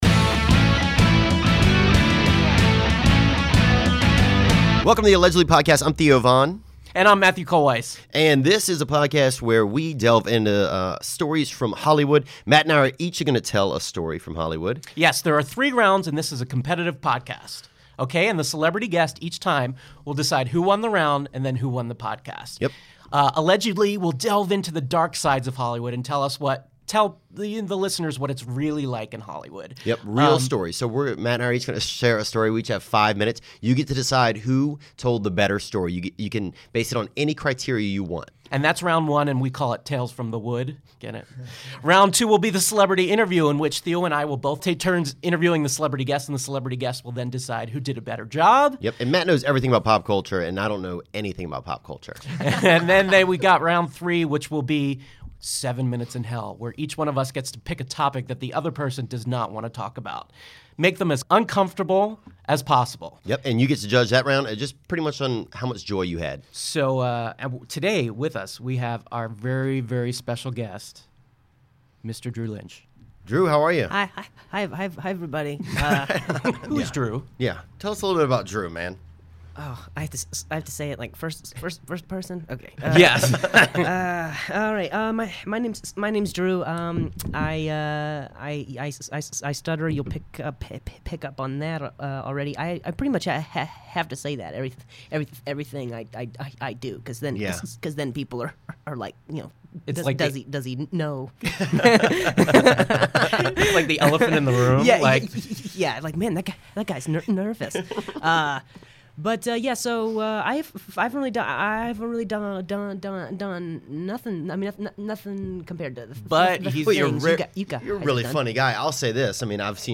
After winning over the hearts of America, the speech-impaired comedian earned a free pass straight to the finals of the competition where he plans on battling it out to the end this month. But before that, he had to come by the podcast to talk about the "AGT" judges and become a judge himself to some of the most embarrassing Hollywood stories you ever heard (hint: someone pretended they had Parkinson's disease to impress a famous actor).